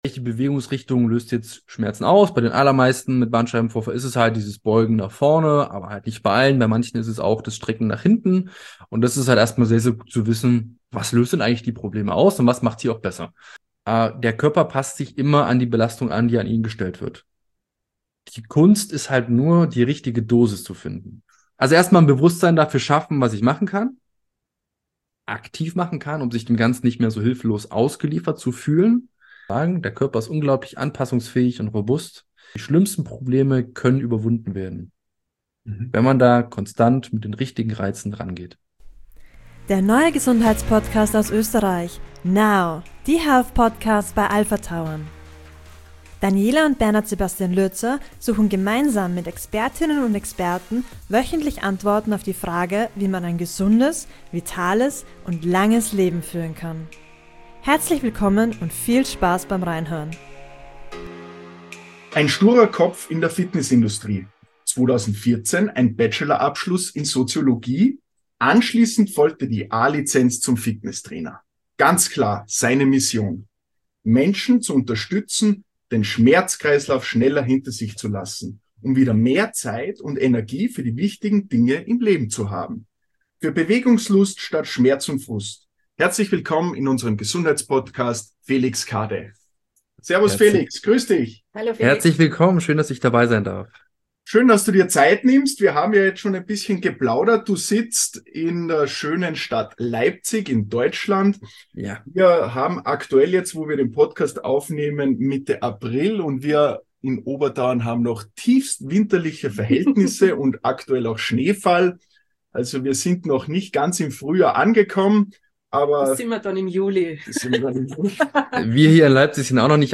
Ein Interessenstalk
Wöchentlich treffen wir unterschiedlichste Expert*innen und stellen Fragen zu Themen aus Wissenschaft, Sport und Medizin.